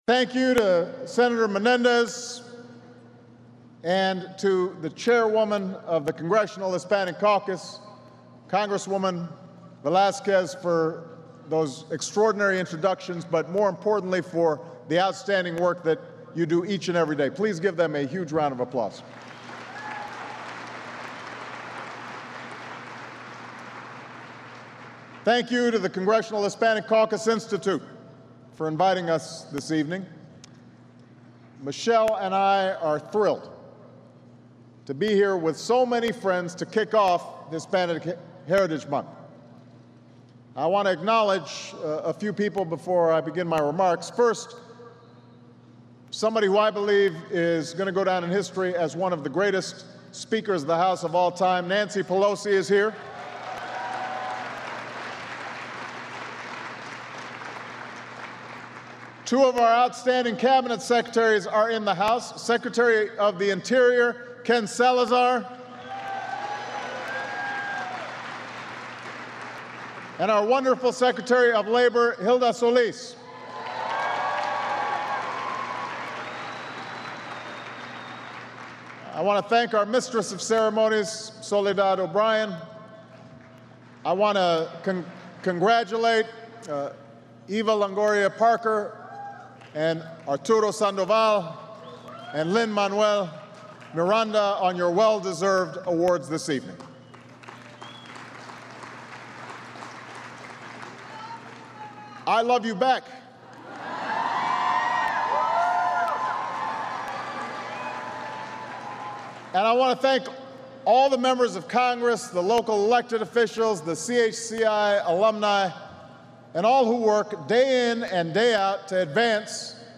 U.S. President Barack Obama speaks at the Congressional Hispanic Caucus Institute's 33rd Annual Awards Gala
Recorded at the Walter E. Washington Convention Center, Washington, D.C., Sept. 15, 2010.